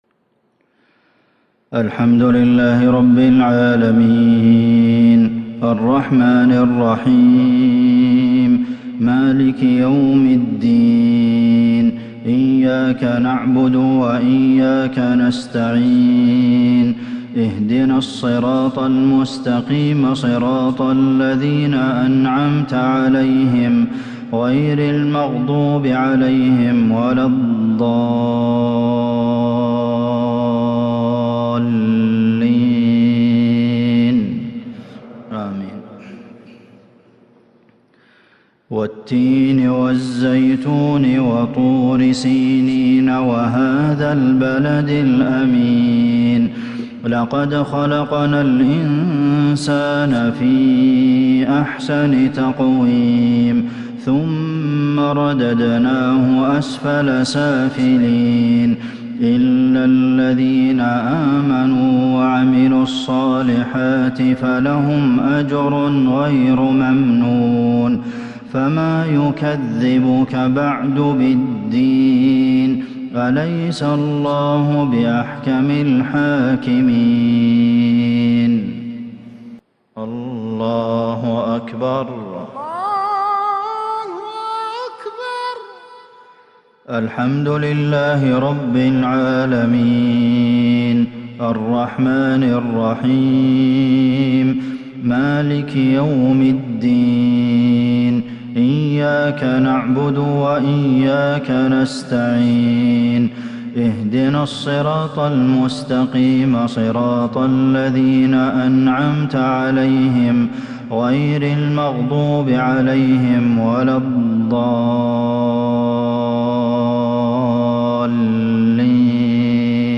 مغرب الاثنين 8-4-1442هـ سورتي التين و الفيل | Maghrib prayer Surah At-Tin and Al-Fil 23/11/2020 > 1442 🕌 > الفروض - تلاوات الحرمين